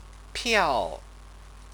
sample_characters_piao_piao.mp3